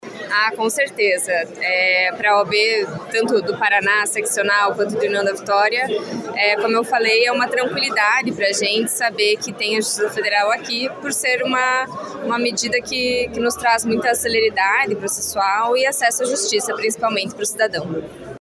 A cerimônia comemorativa reuniu autoridades do Judiciário, representantes do Ministério Público, da Ordem dos Advogados do Brasil (OAB), politicos, servidores públicos, advogados e a comunidade em geral, em um momento marcado por homenagens e reflexões sobre o papel da Justiça Federal no desenvolvimento regional.